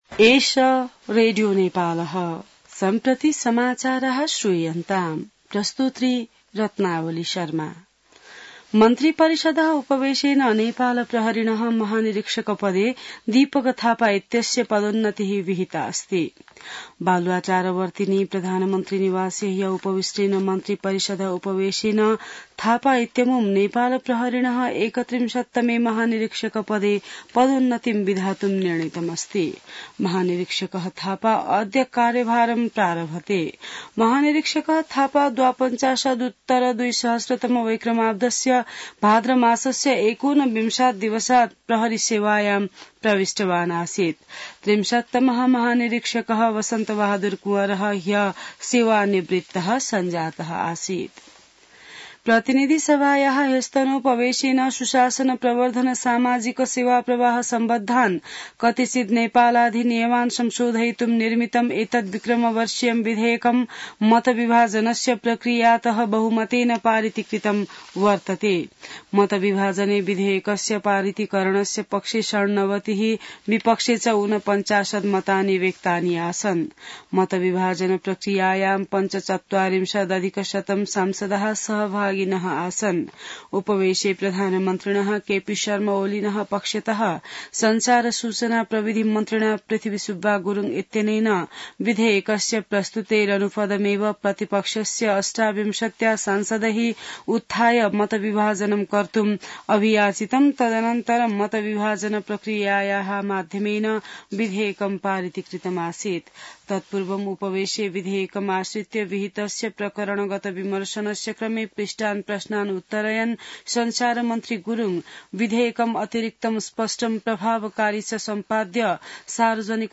संस्कृत समाचार : ५ चैत , २०८१